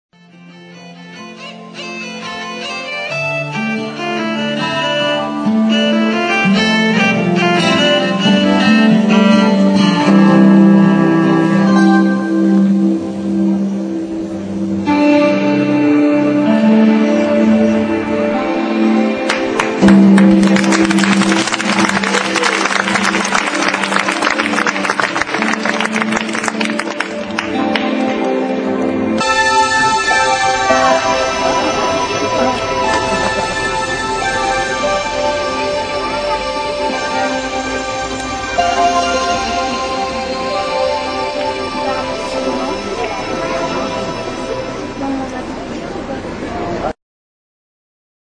Ici, vous pouvez télécharger 4 extraits de la bande son de notre spectacle : si vous avez besoin d'un lecteur cliquez sur RealPlayer